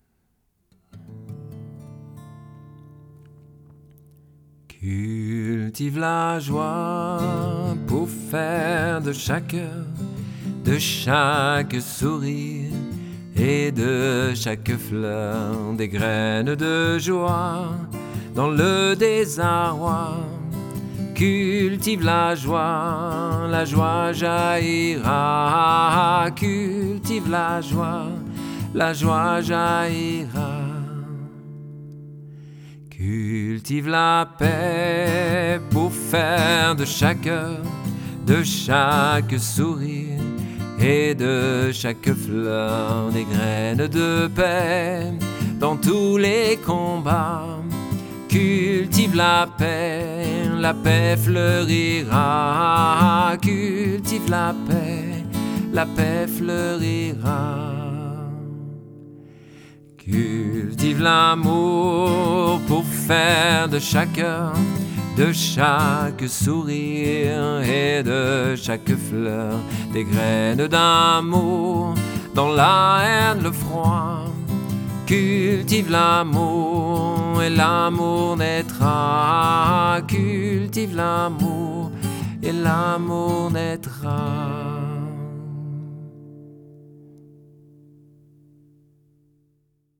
Chanson de pratique classique du Village des Pruniers